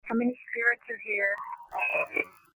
EVP's
A slowed version of the above EVP.